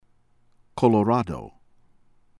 COLONNA, YVAN ee-VAH(n)   kuh-luh-NAH